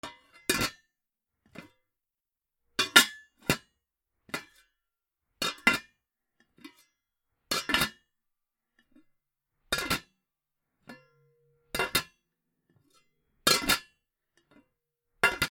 なべ 小さいなべのふたを開け閉め
『カタ』